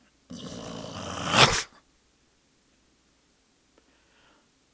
growl3.ogg